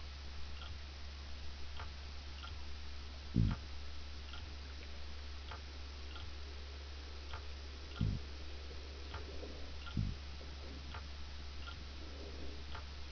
Cod on Stellwagen BankAtlantic cod, Gadus morhua Cod caught on Stellwagen Bank October 2001 (see Stellwagen project).
Loud sounds in the background are instrument noise. The faint sound like rushing wind is the call of a harbor seal.
Listen to: three cod grunts overlaying a much longer seal call